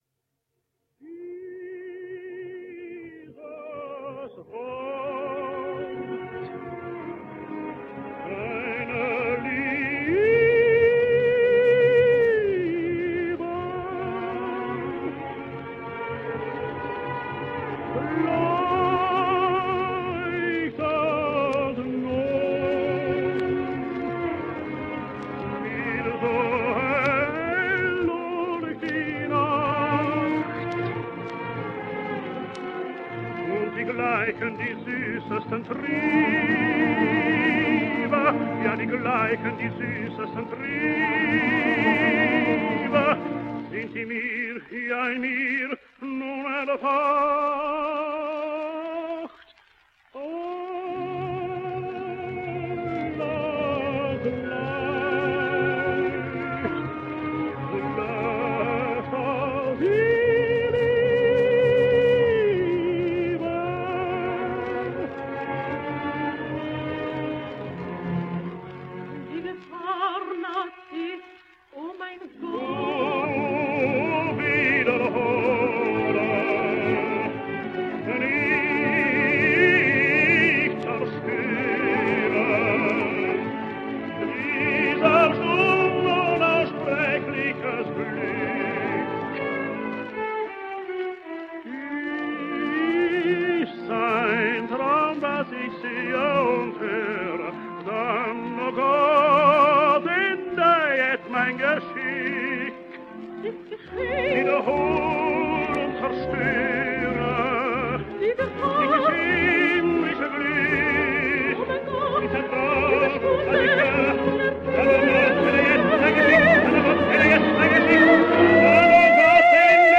duet